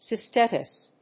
音節cys・ti・tis 発音記号・読み方
/sɪstάɪṭɪs(米国英語)/